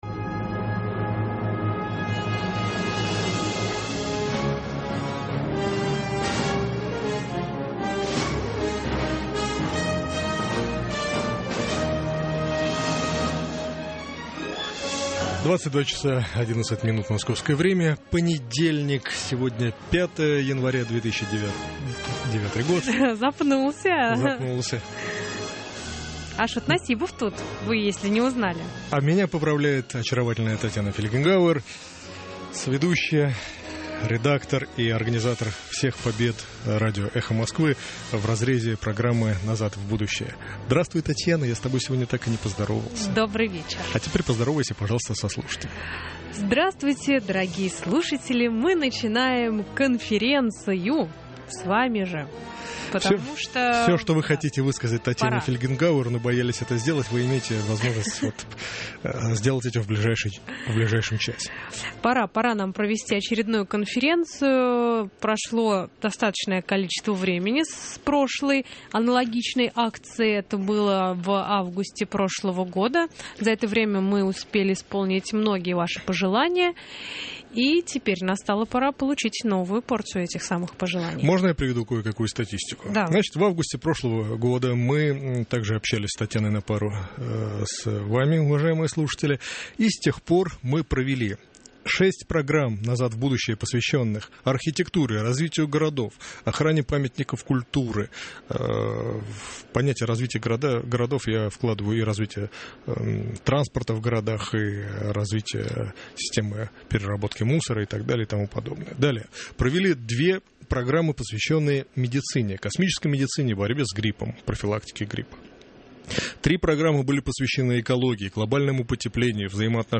Конференция со слушателями - Назад в будущее - 2009-01-05